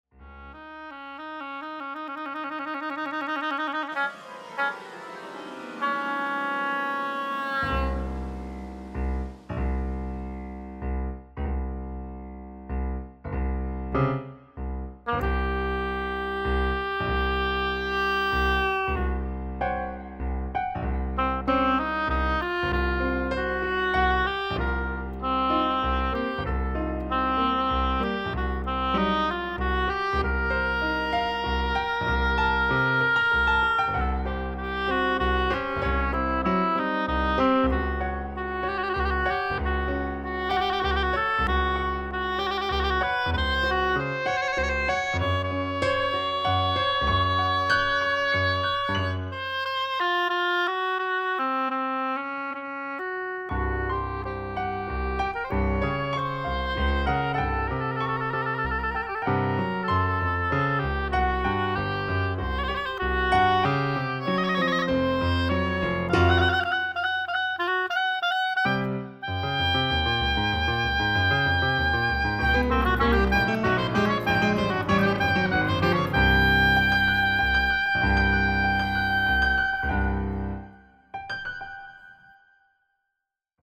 oboe & piano